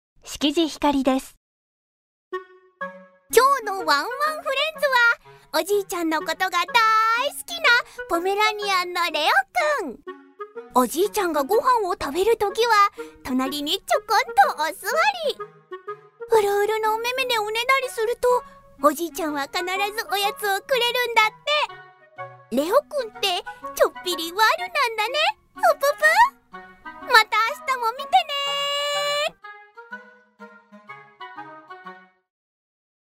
ボイスサンプル
• 音域：高～中音
• 声の特徴：元気、明るい、キャラボイス
• 番組